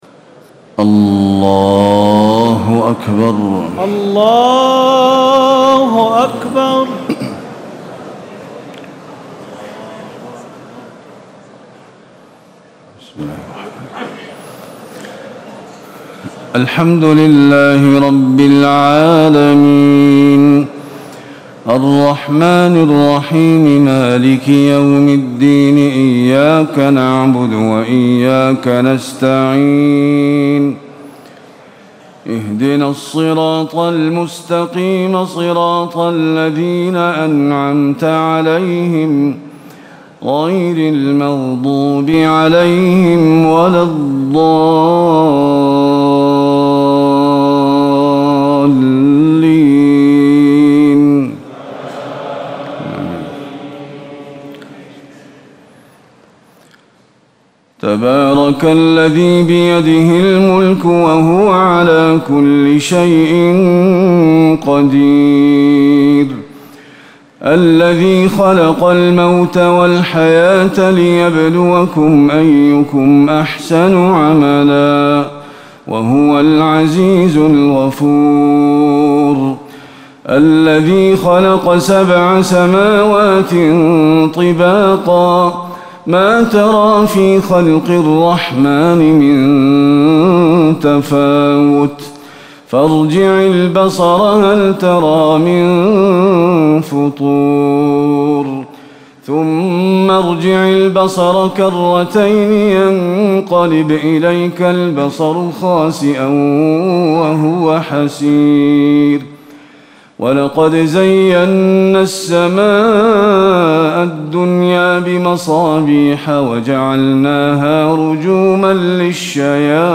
تراويح ليلة 28 رمضان 1437هـ من سورة الملك الى نوح Taraweeh 28 st night Ramadan 1437H from Surah Al-Mulk to Nooh > تراويح الحرم النبوي عام 1437 🕌 > التراويح - تلاوات الحرمين